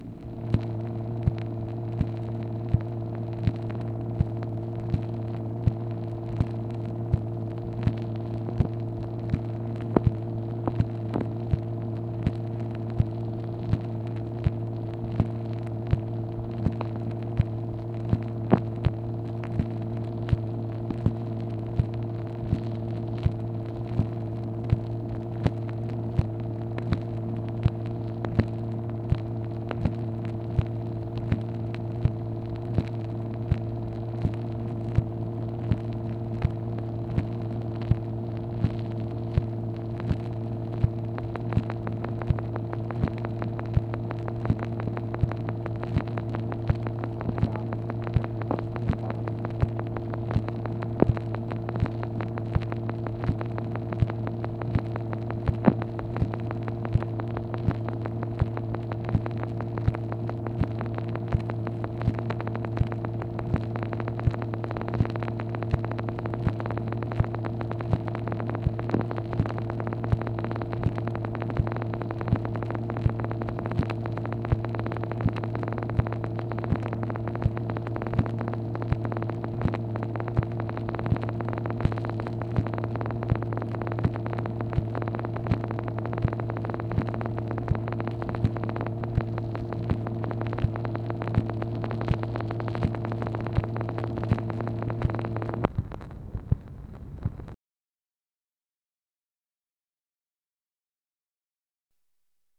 MACHINE NOISE, April 7, 1966
Secret White House Tapes | Lyndon B. Johnson Presidency